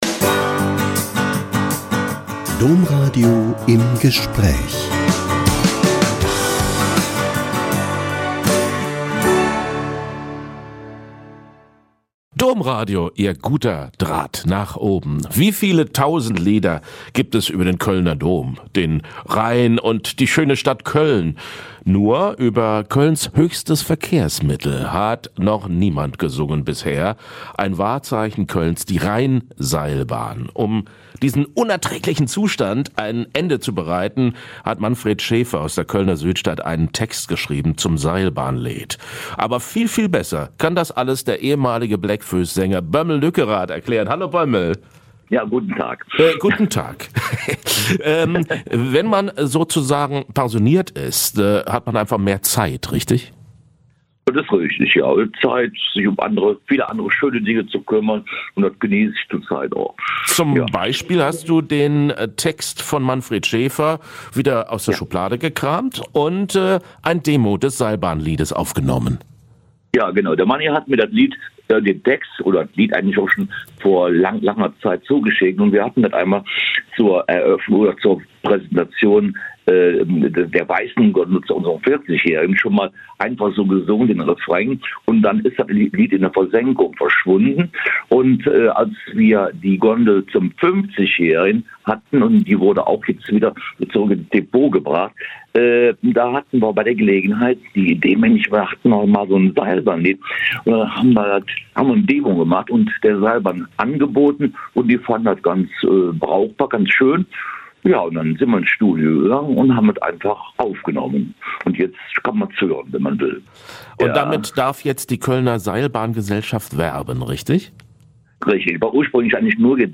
"Et Seilbahn-Leed" - Ein Lied für die Kölner Seilbahn - Ein Interview mit Bömmel Lückerath (ehemals Bläck Fööss) # Menschen und Musik